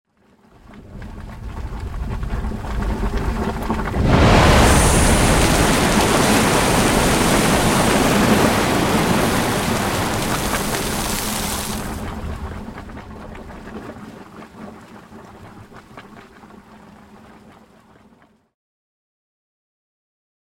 Звуки гейзеров
Звук извергающегося гейзера в дикой природе